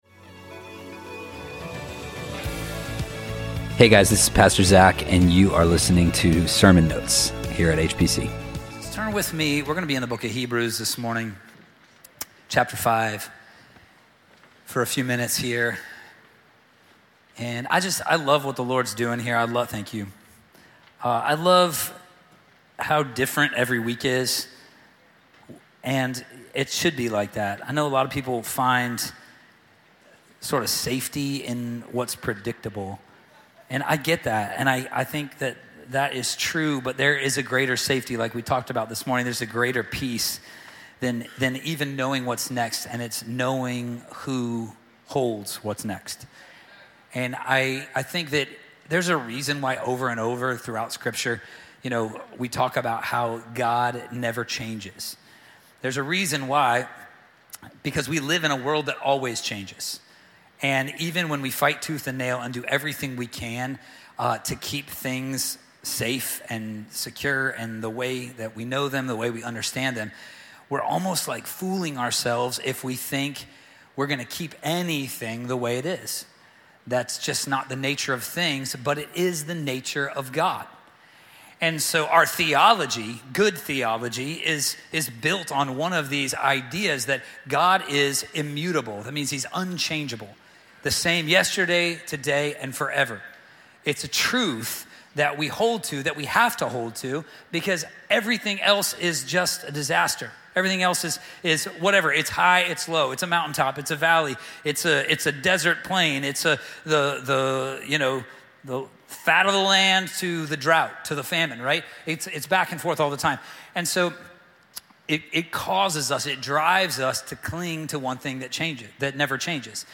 HPC Sermon Notes Podcast - Hebrews 5 | Free Listening on Podbean App